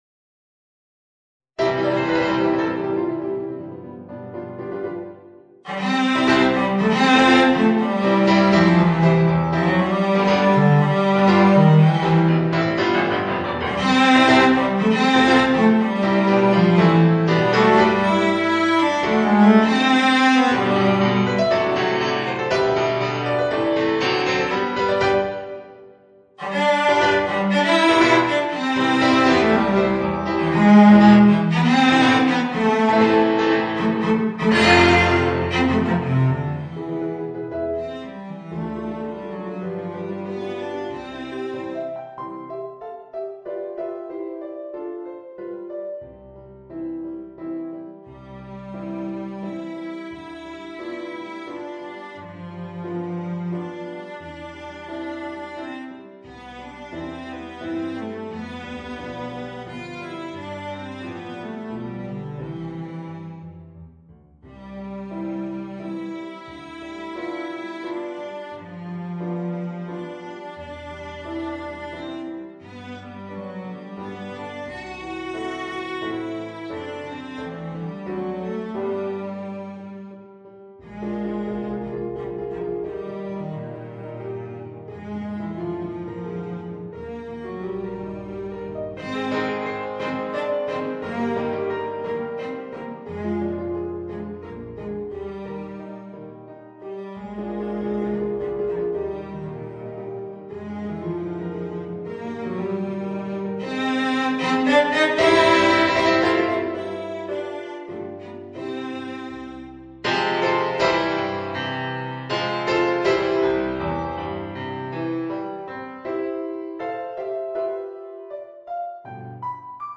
Voicing: Violoncello and Piano